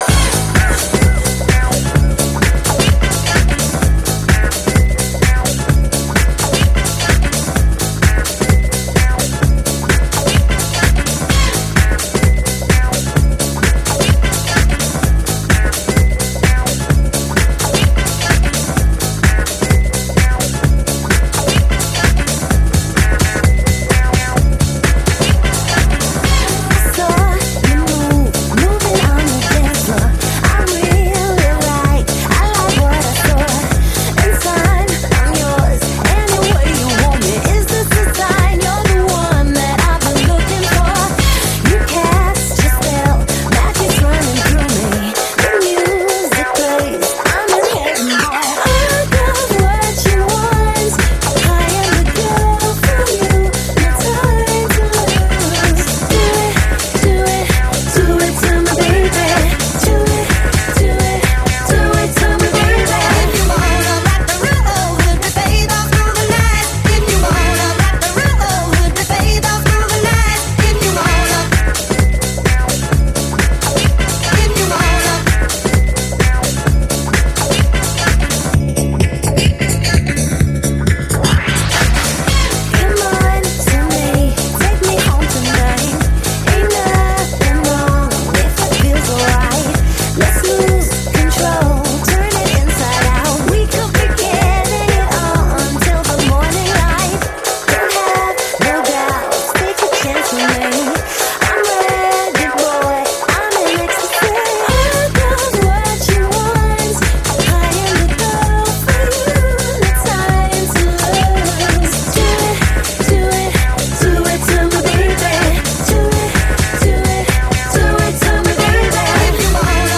amely a könnybb house vonalat hivatott képviselni